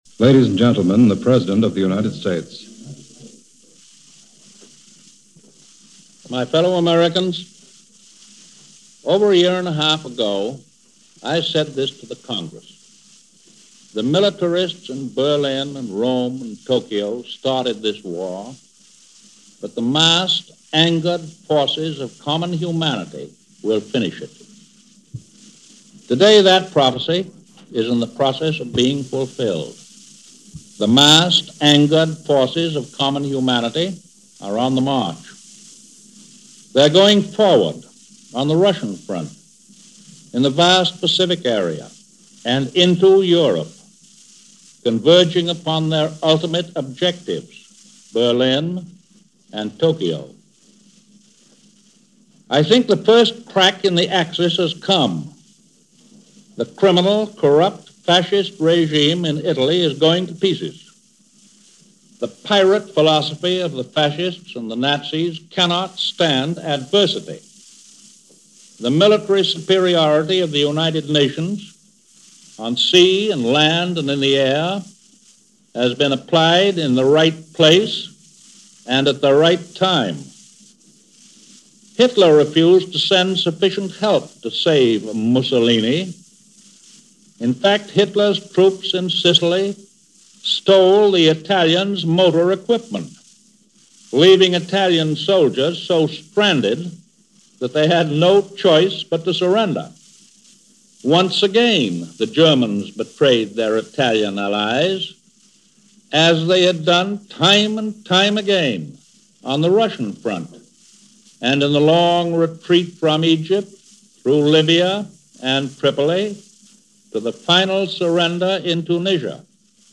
President Roosevelt Gives A Fireside Chat - July 28, 1943 - broadcast live over all networks - CBS Radio -